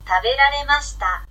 ta be ra re ma shi ta